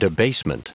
Prononciation du mot debasement en anglais (fichier audio)
Prononciation du mot : debasement